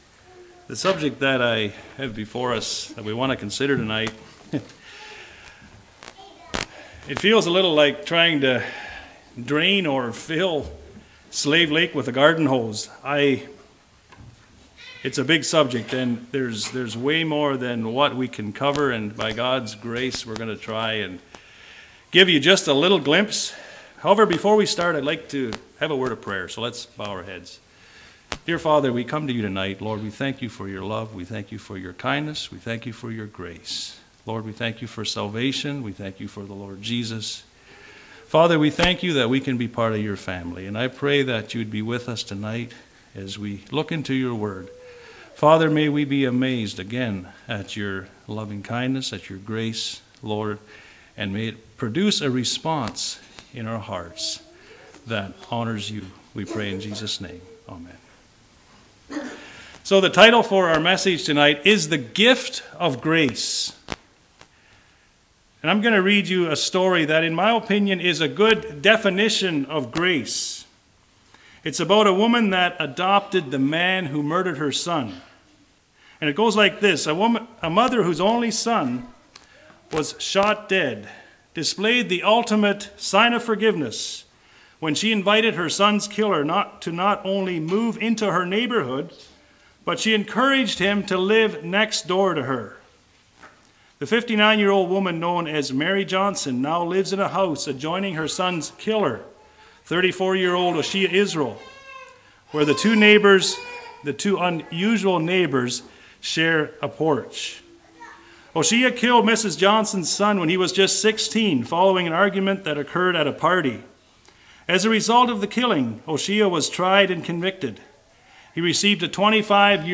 2021 Sermon ID